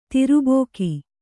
♪ tiru bōki